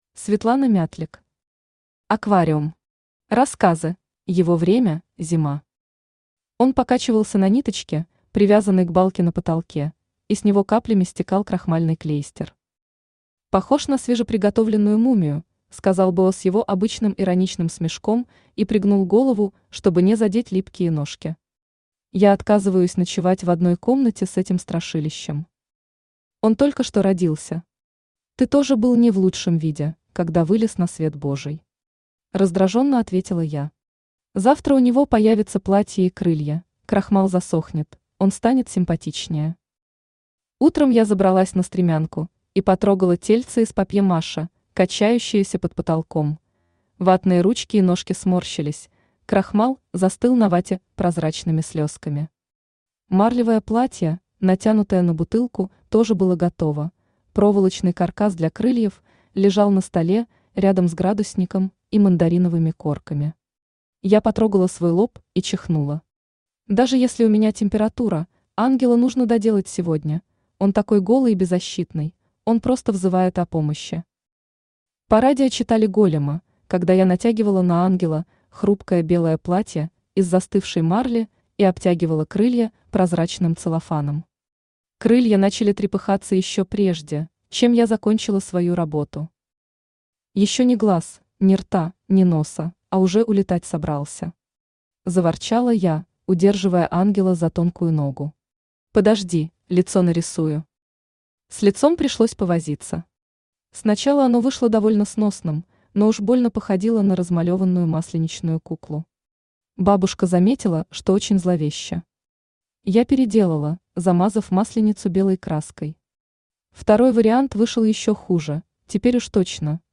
Аудиокнига Аквариум. Рассказы | Библиотека аудиокниг
Рассказы Автор Светлана Мятлик Читает аудиокнигу Авточтец ЛитРес.